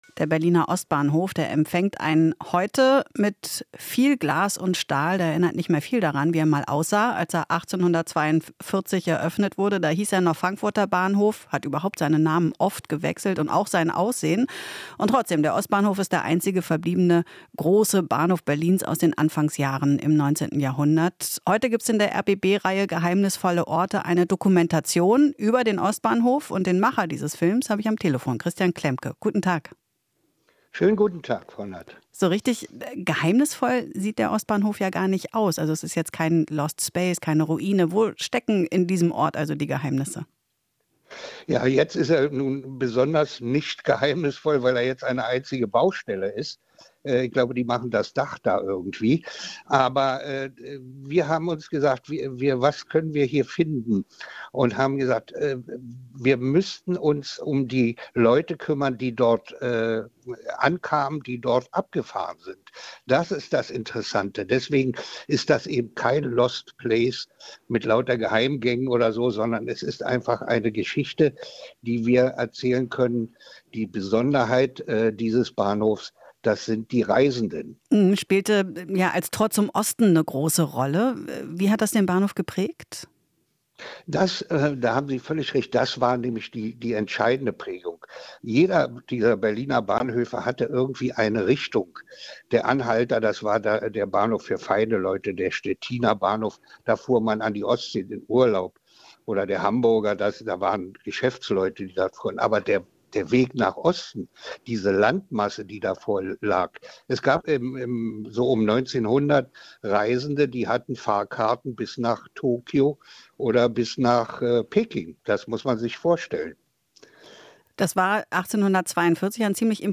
Interview - Was ist am Ostbahnhof geheimnisvoll?